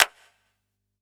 Index of /musicradar/Kit 16 - Electro
CYCdh_ElecK06-Clap03.wav